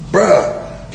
Download Joe Biden Says Bruh sound effect for free.
Joe Biden Says Bruh